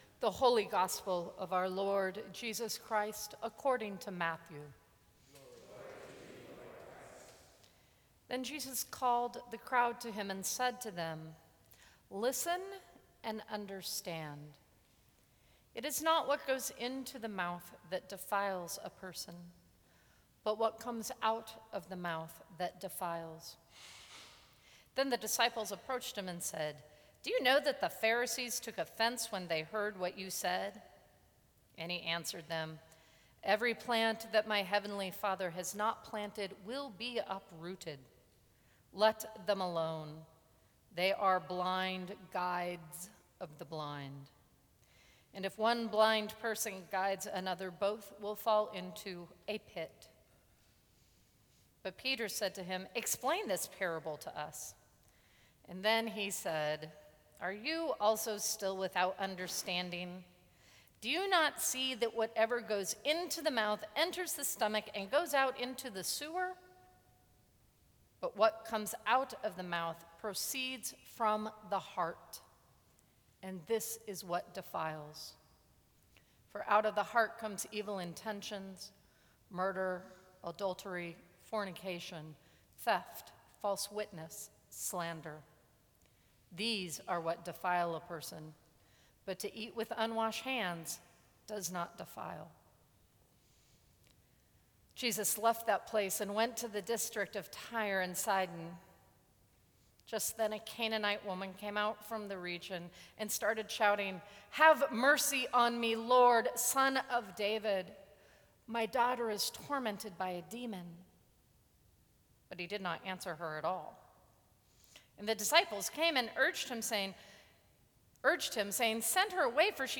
Sermons from St. Cross Episcopal Church August 17, 2014.